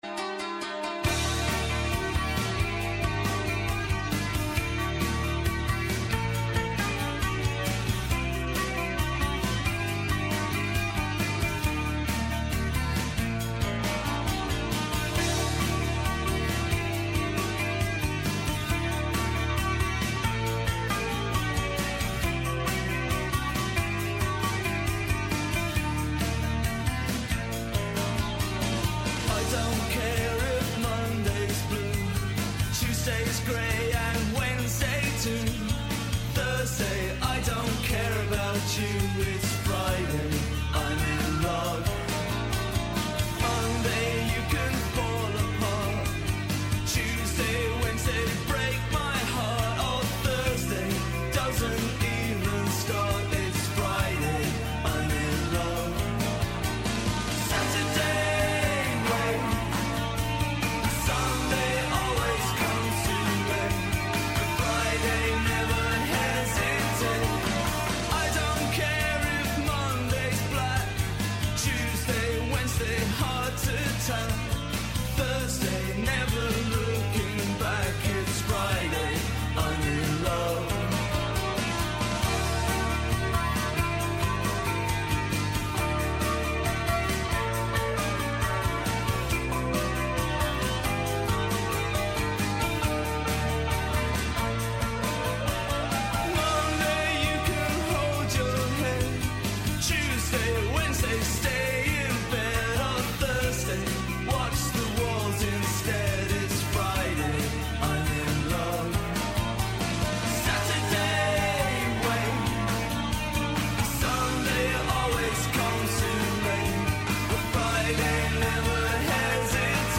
Καλεσμένος σήμερα ο Άδωνις Γεωργιάδης, Υπουργός Εργασίας .